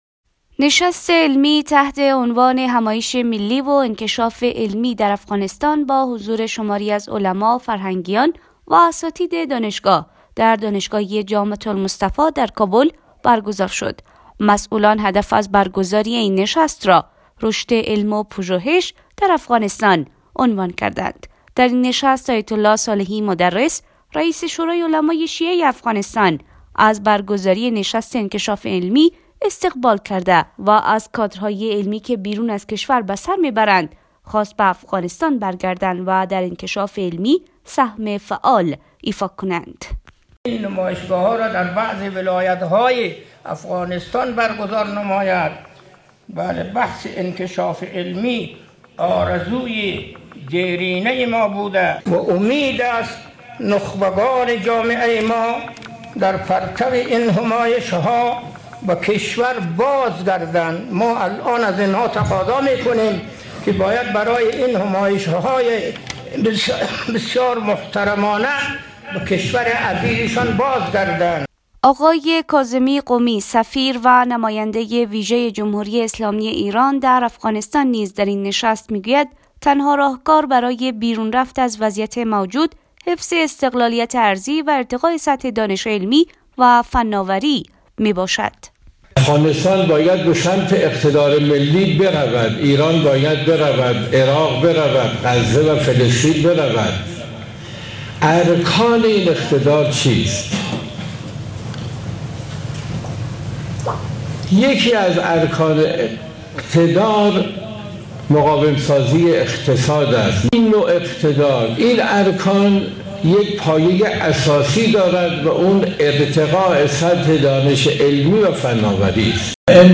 به گزارش رادیو دری